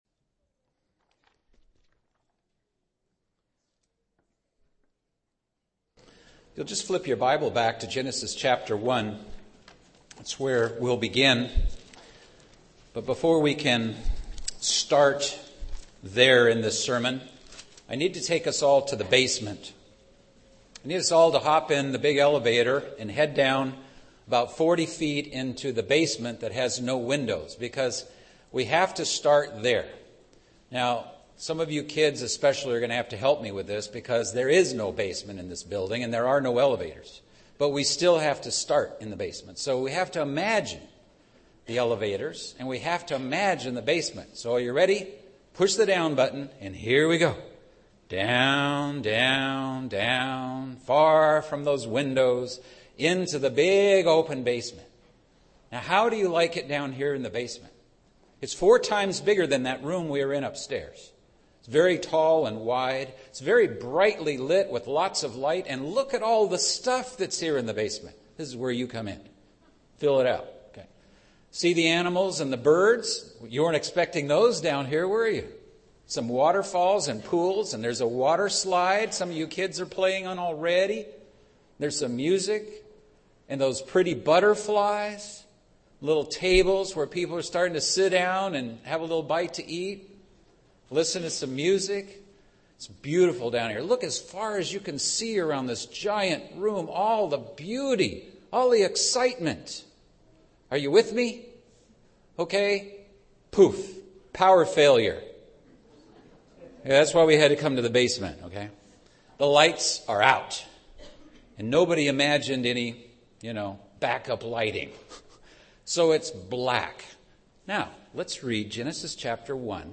Truths about "Light" leap from the Bible's pages in this sermon, "From Darkness to Light" (sermon given on the Feast of Trumpets).